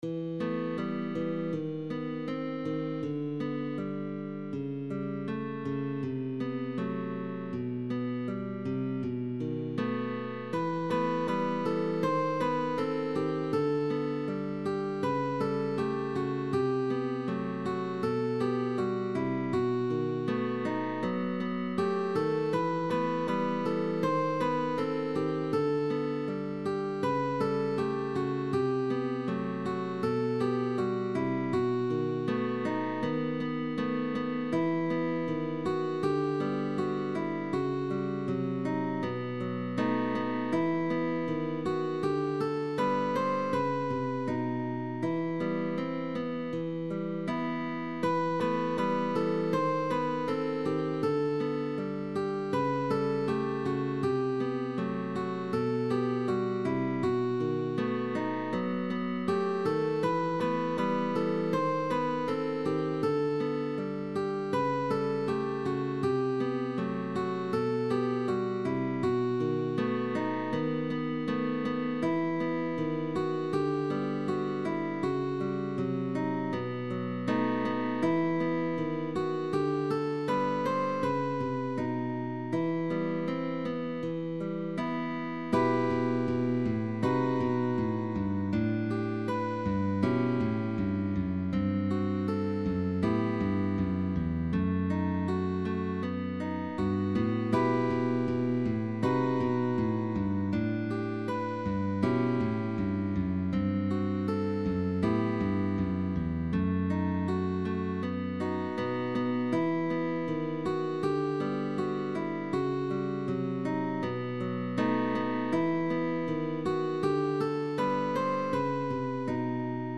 Guitar duo sheetmusic.
MELODIC GUITAR DUO: PUPIL AND TEACHER